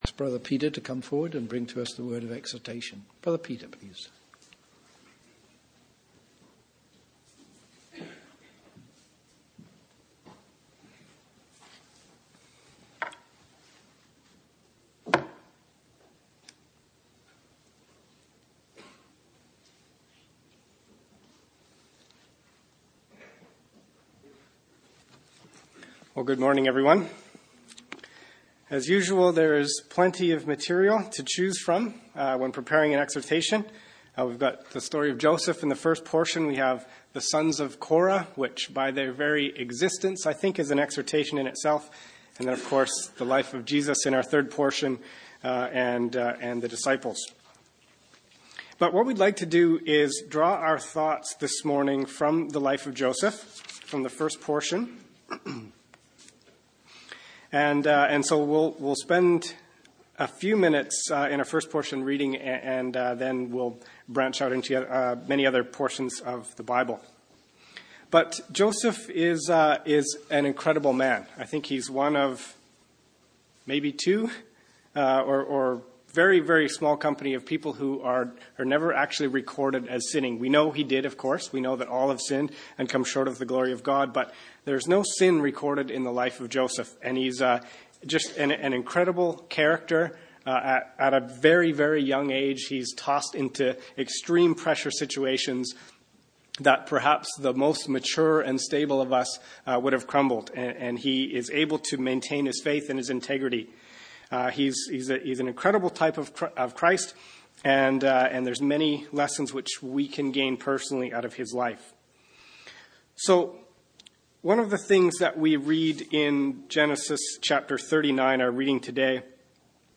Exhortation 01-24-16